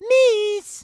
Mario jeers at the player, wanting them to whiff despite that being impossible in the game.